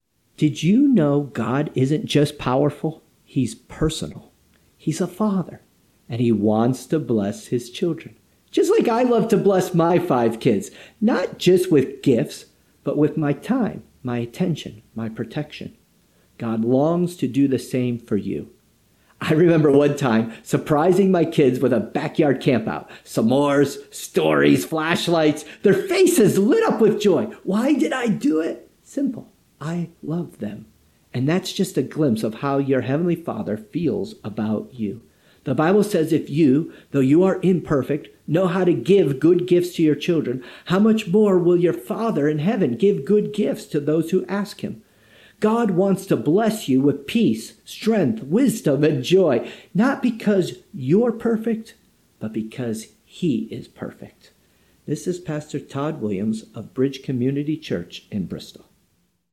One Minute inspirational thoughts presented by various clergy!